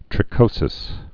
(trĭ-kōsĭs)